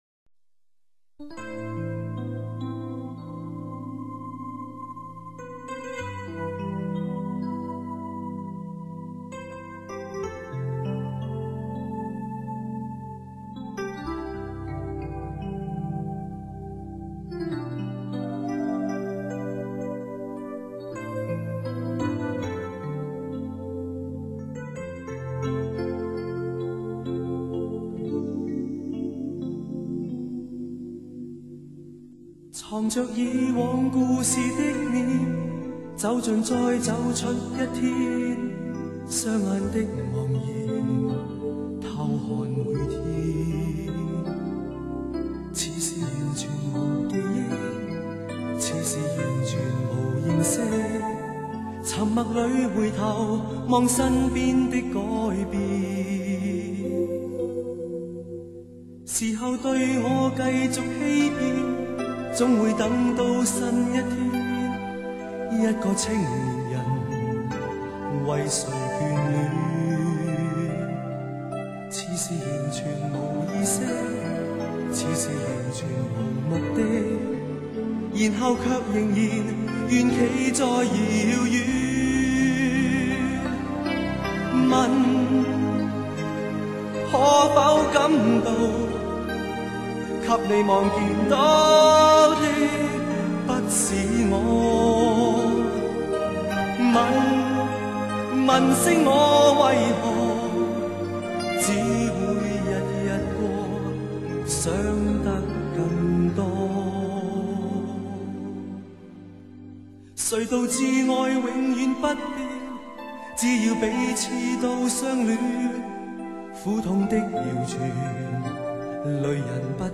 歌词中虽有“谁没有从前，让快乐重建”，但整首歌显得很灰暗，所以也引起社会上的不满。